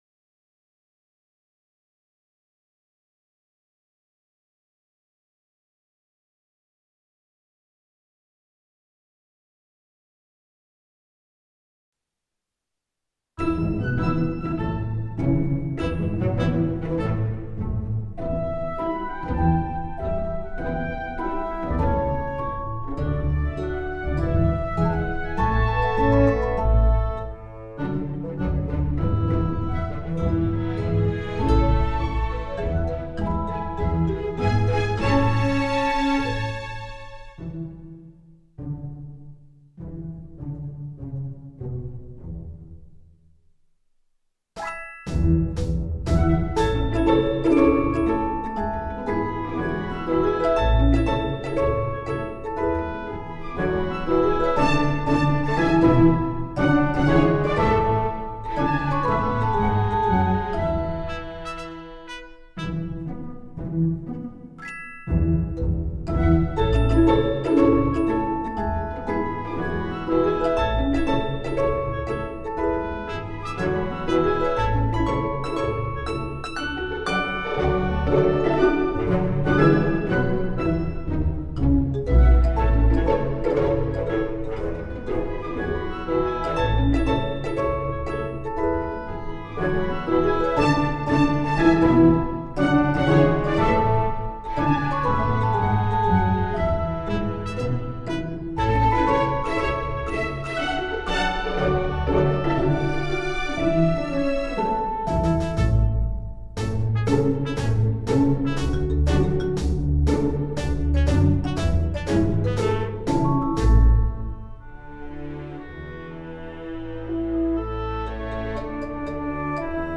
this time i present a very simple song